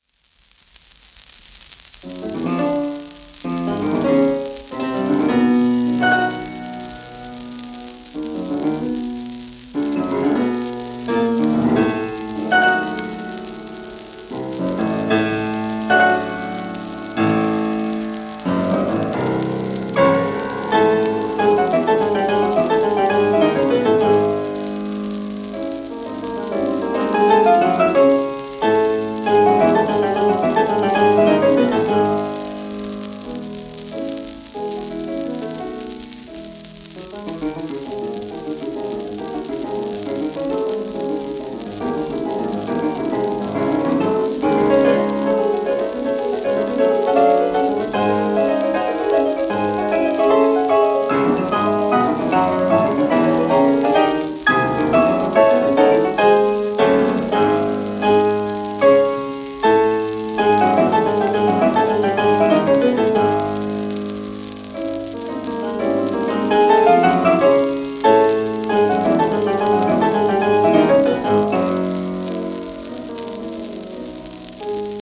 アルトゥール・ルービンシュタイン(P:1887-1982)
シェルマン アートワークスのSPレコード
rubinstein_chopin_op39.m4a